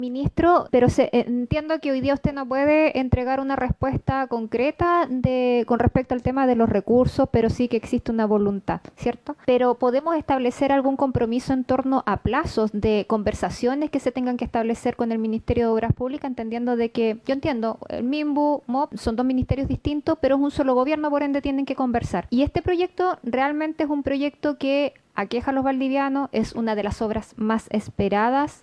En ese contexto, la presidenta de la Comisión de Vivienda del Senado, María José Gatica (RN), pidió que el ministro Carlos Montes se comprometa con plazos para alcanzar acuerdos con el Ministerio de Obras Públicas sobre el financiamiento del viaducto.